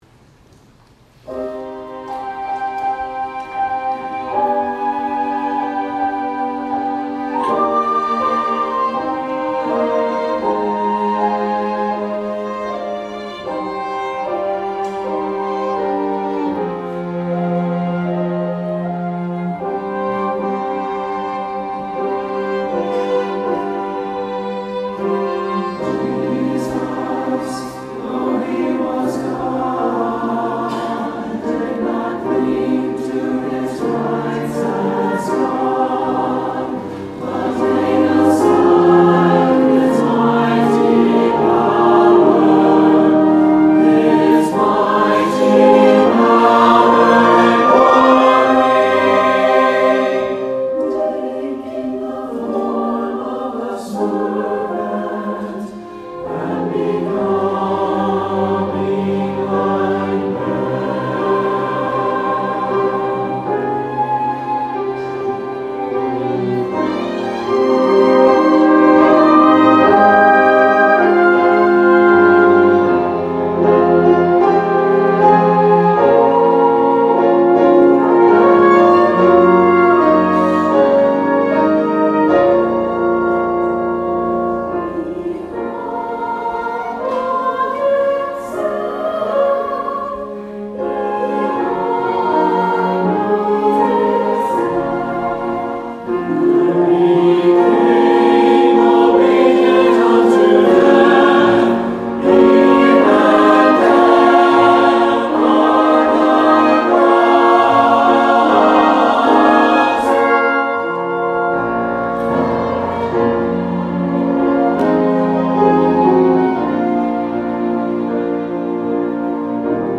Offertory: Trinity Chancel Choir